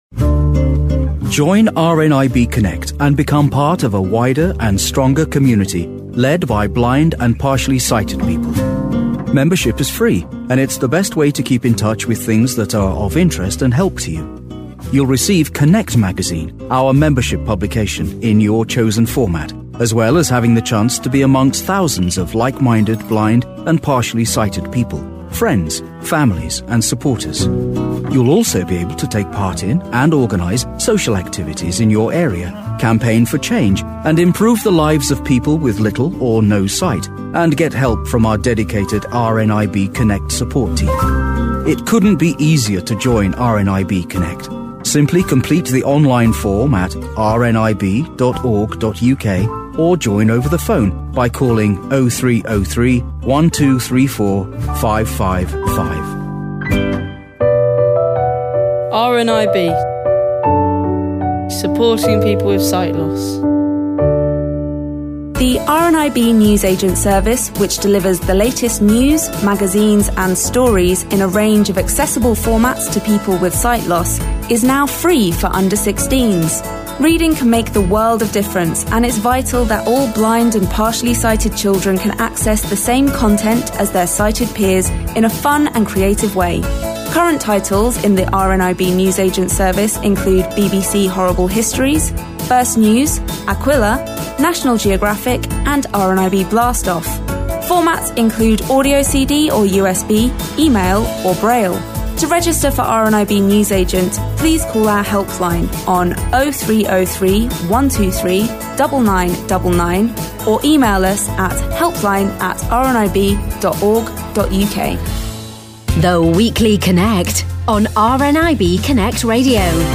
This week we hear from young blind and partially sighted people who discuss when and how to disclose your sight loss to friends and family, and even employers.